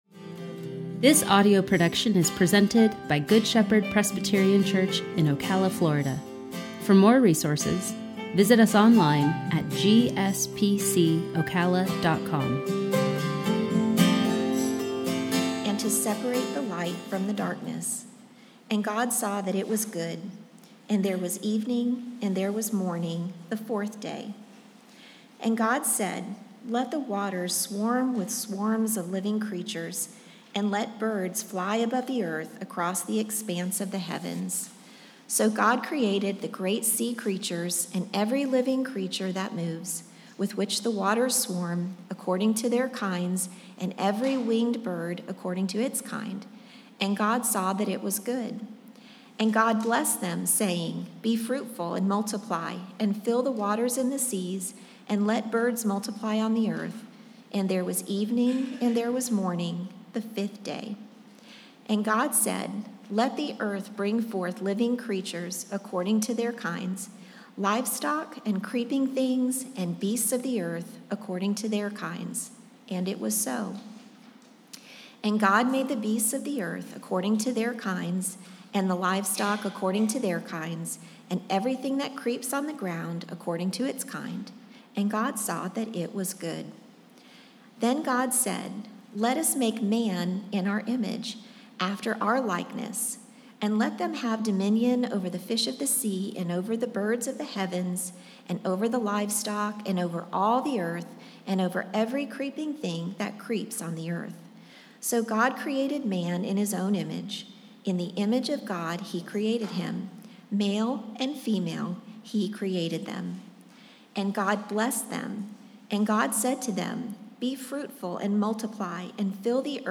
sermon-4-18-21.mp3